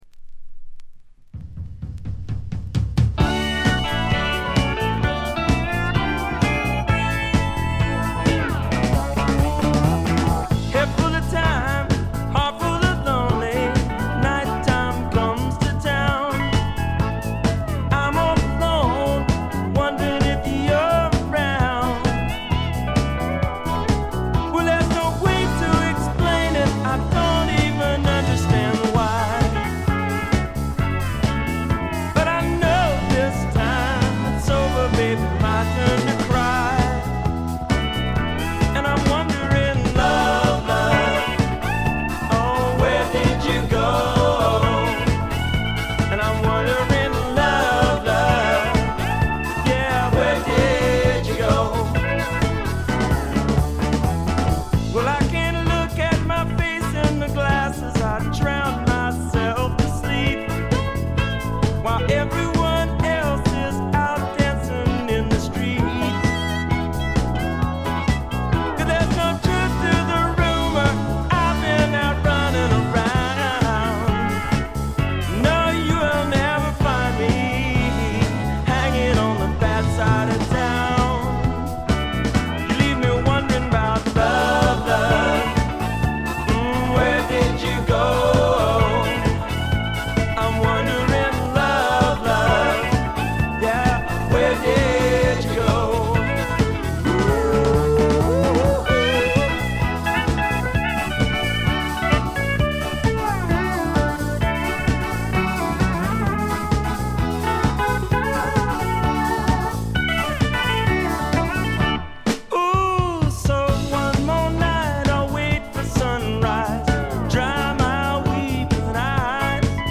ほとんどノイズ感無し。
録音は英国ウェールズのおなじみロックフィールド・スタジオ。
ルーツ色を残した快作です。
試聴曲は現品からの取り込み音源です。